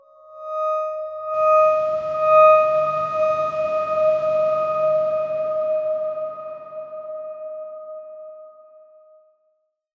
X_Darkswarm-D#5-f.wav